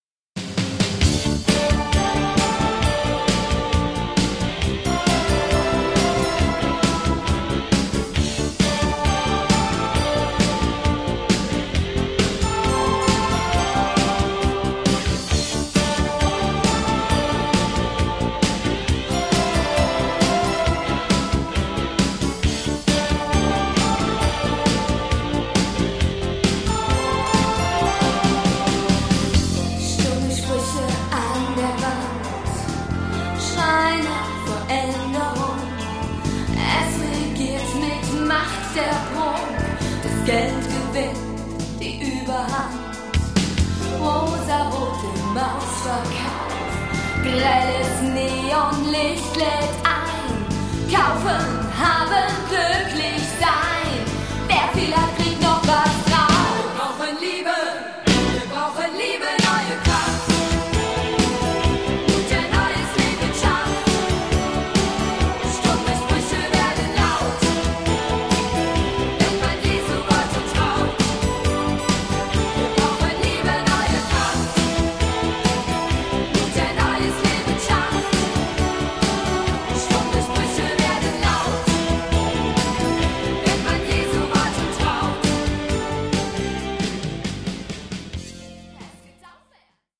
rockige Sounds
Pop